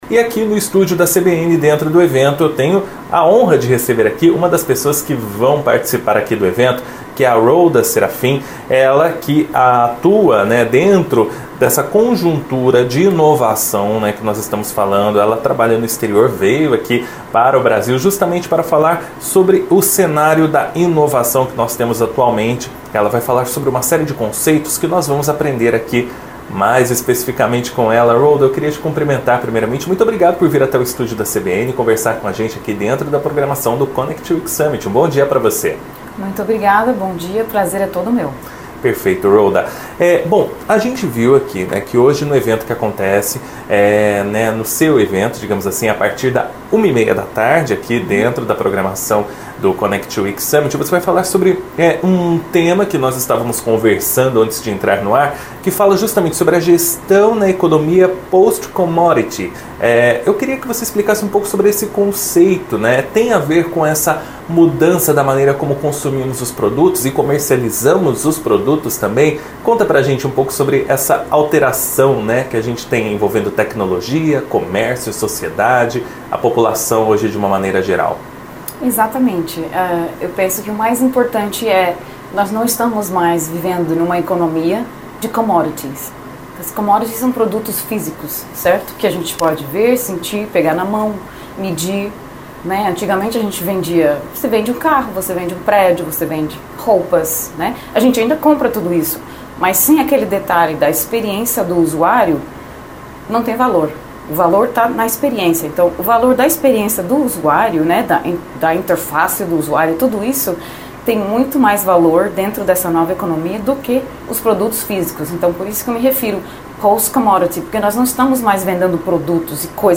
Confira, na íntegra, o bate-papo sobre o assunto, que foi ao ar no CBN Curitiba 1ª Edição.